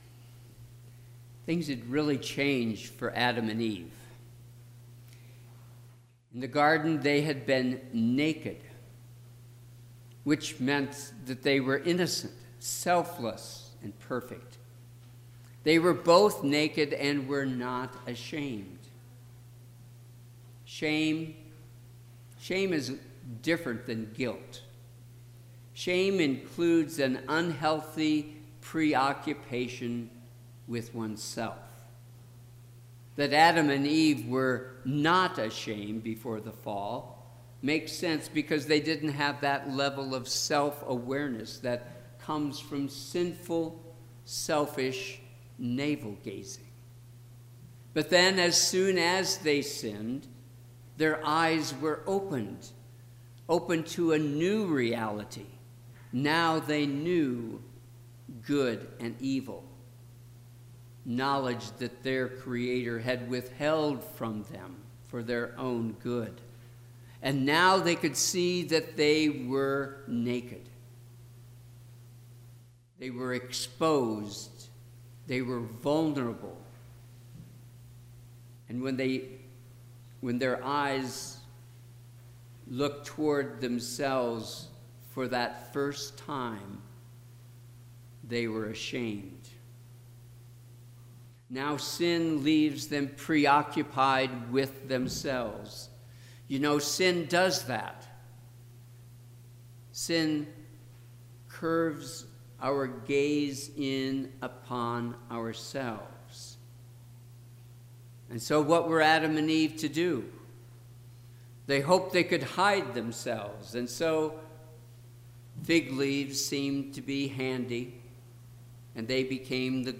Sermon - 3/27/2019 - Wheat Ridge Lutheran Church, Wheat Ridge, Colorado
Third Wednesday of Lent
Sermon – 3/27/2019